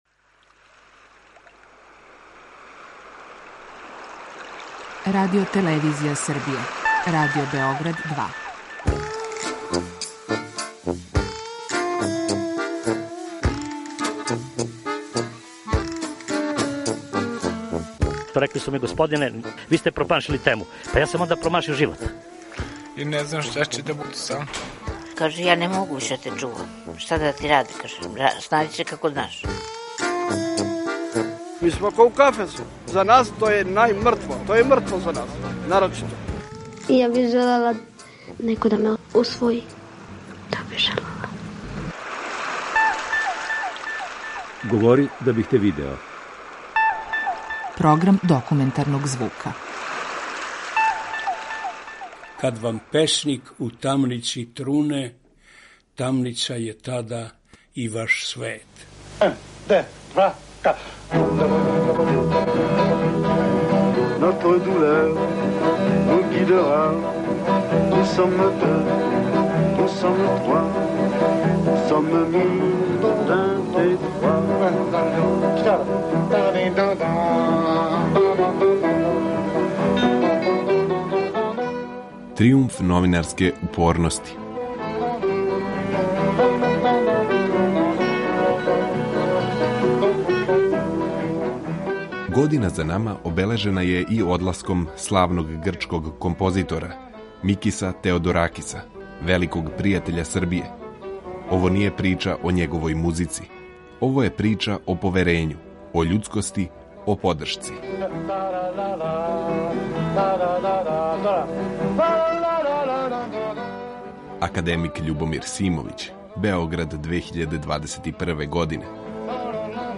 Документарни програм: Тријумф новинарске упорности